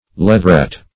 Leatheret \Leath"er*et\, Leatherette \Leath`er*ette"\, n.